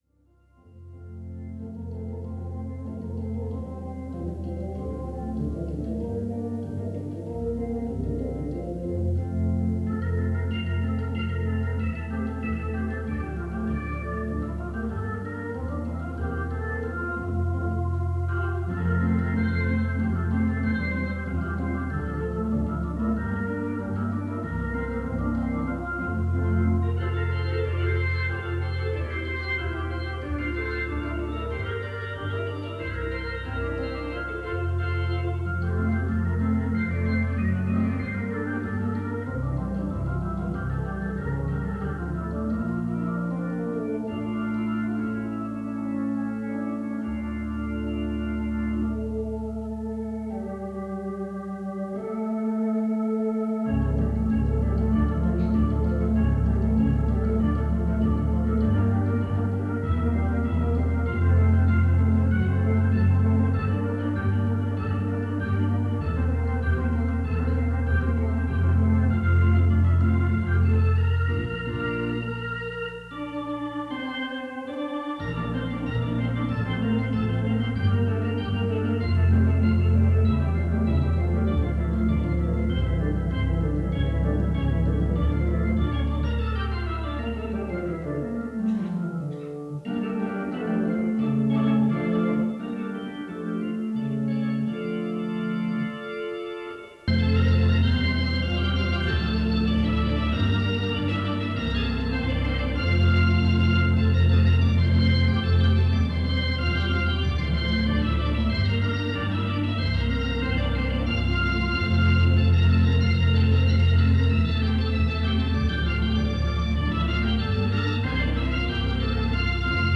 Concierto de órgano
en el Hotel Meliá Don Pepe / Marbella 1972
Escuchar framentos del concierto interpretado en un órgano Hammond T-222.